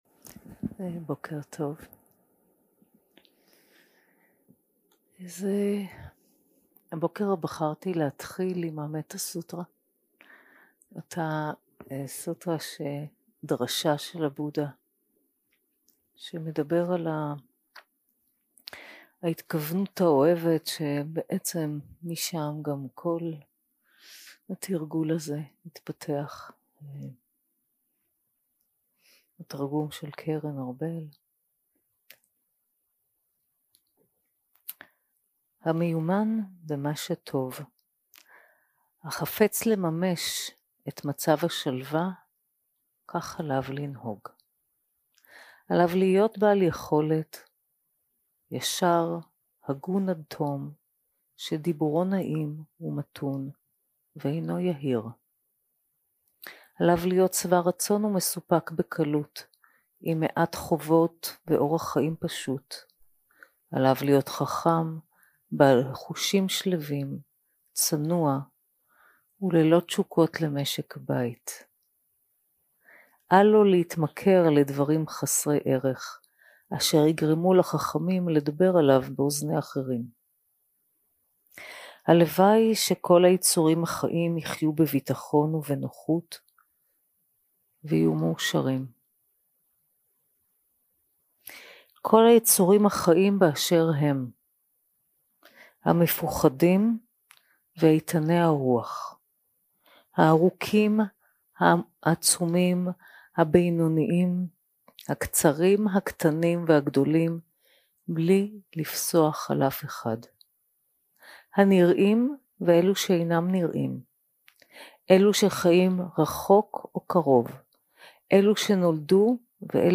יום 6 – הקלטה 14 – בוקר – הנחיות למדיטציה - הדמות הנייטרלית 0:00 0:00 סוג ההקלטה: Dharma type: Guided meditation שפת ההקלטה: Dharma talk language: Hebrew